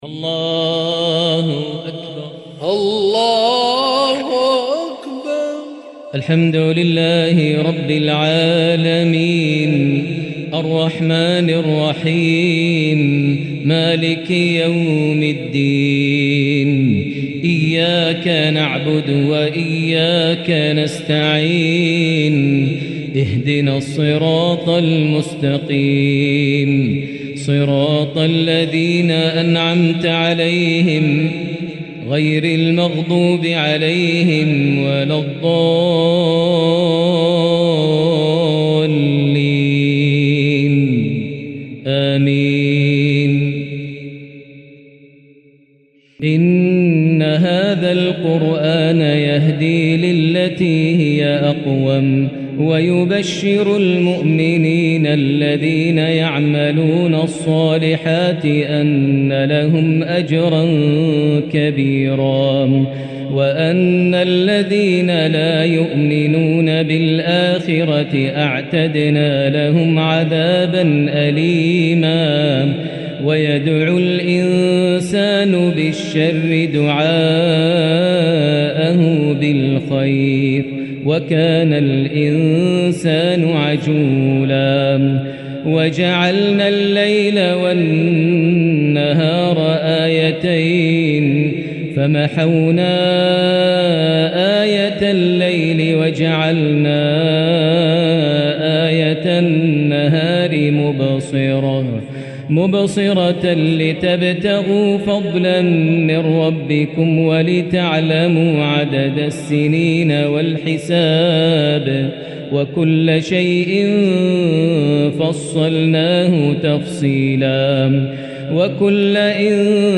سلسلة الرُوح | تلاوات قرآنية تأسر قلبك بتنوع الأداء من الغريد الشيخ د. ماهر المعيقلي | شوال ١٤٤٤هـ > سلسلة الرُوح للشيخ ماهر المعيقلي > مزامير الفرقان > المزيد - تلاوات الحرمين